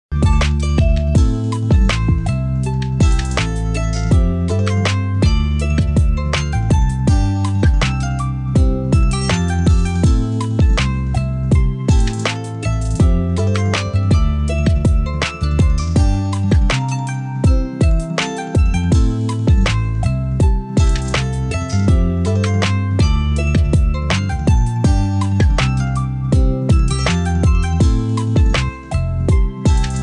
Ritmo Latino 2 - 120 bpm